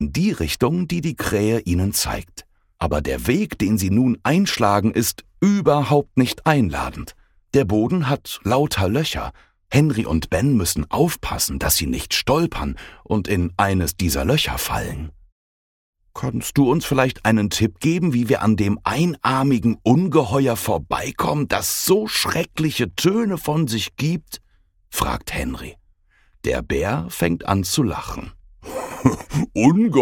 (MP3-Hörbuch - Download)